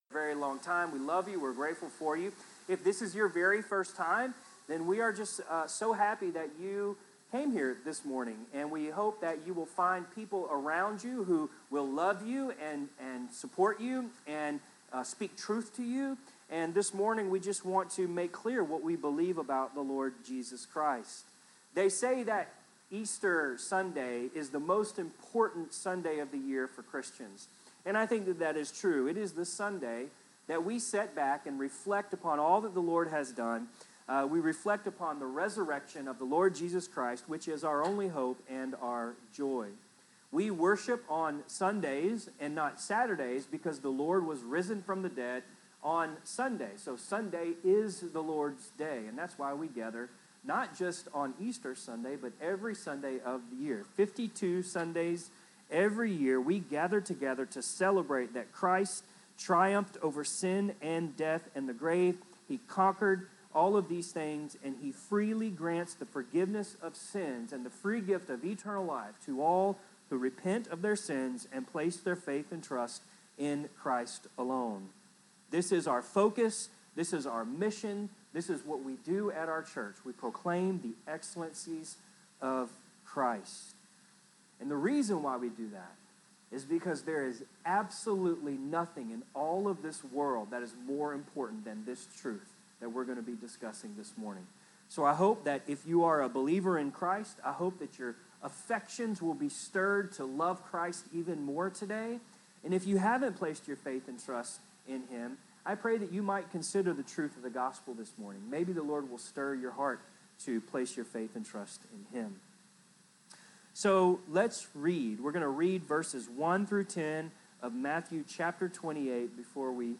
4/20/25 Easter Service 2025